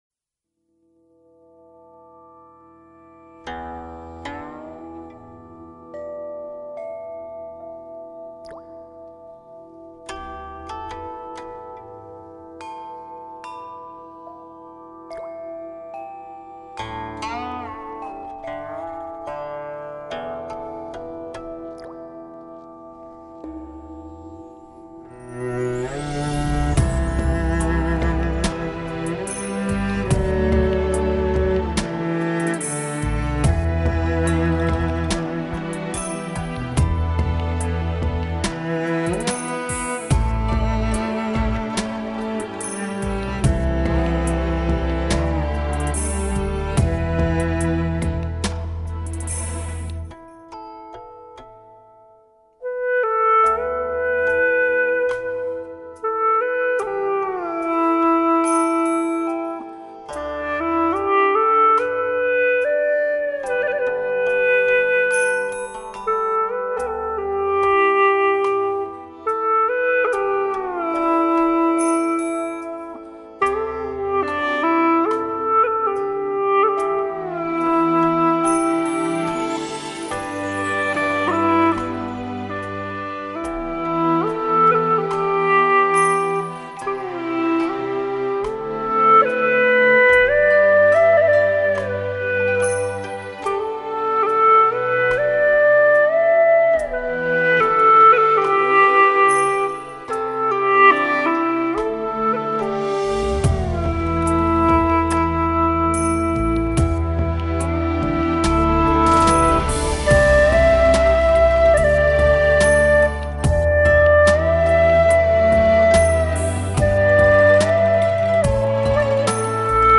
调式 : G 曲类 : 古风